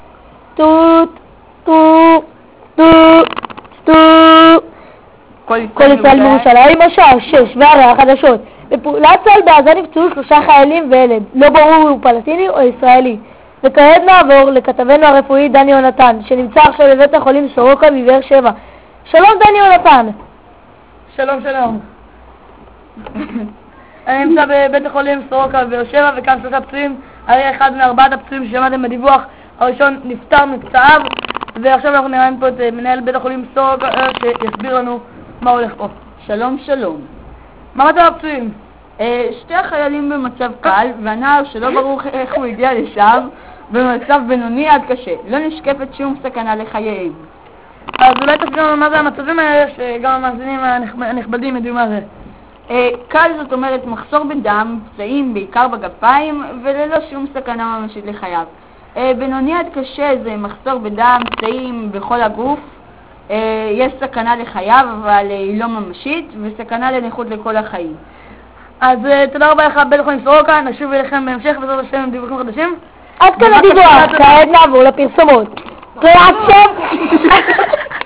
אייכות מזעזעת...